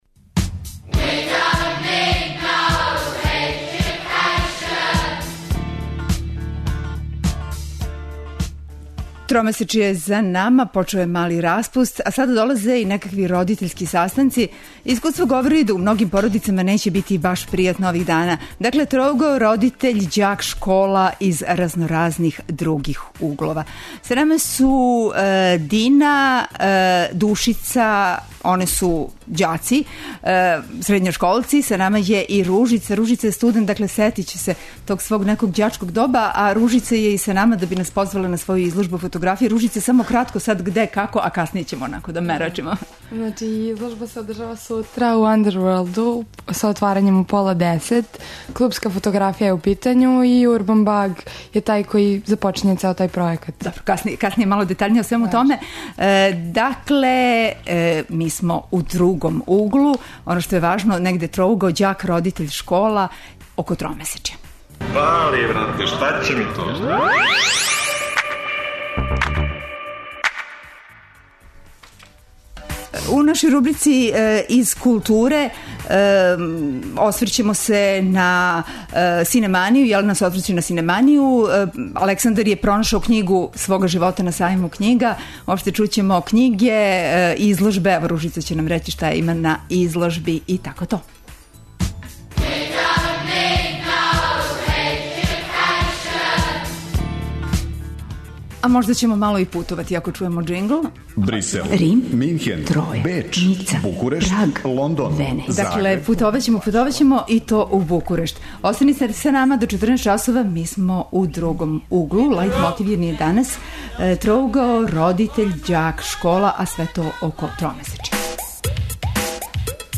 У рубрици "Из културе" студенти нам преносе утиске са Синеманије, препоручују књигу са сајма и позивају на изложбу фотографије.